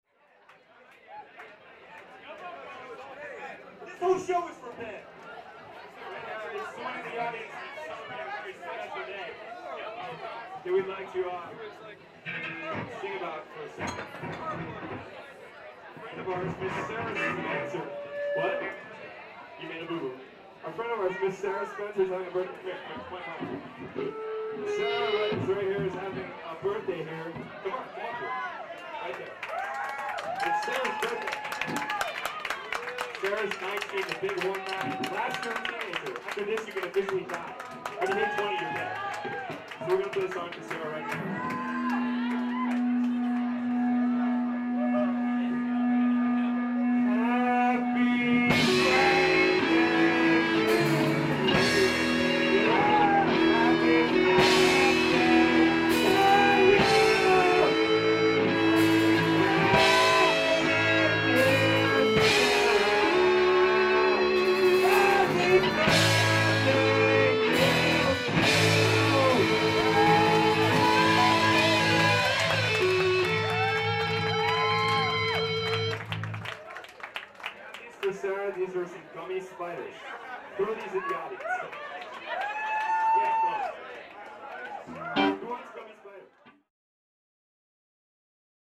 Bass
Lead Guitar, Vocals
Drums
Live at the Underground (10/13/95) [Entire Show]